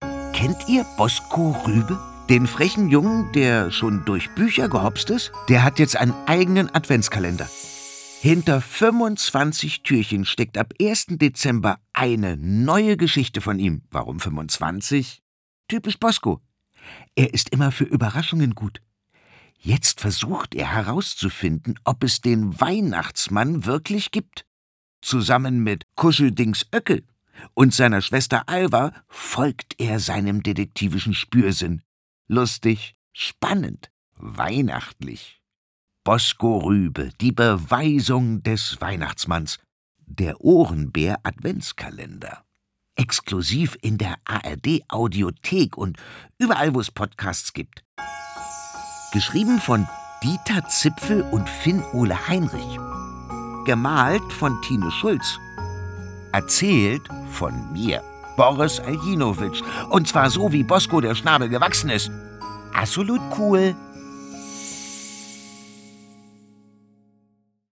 Erzählt von mir, Boris
Aljinovic, und zwar so, wie Bosco der Schnabel gewachsen ist:
Azolut cool!